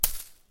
Звуки отмены для монтажа
Чуть заметный вариант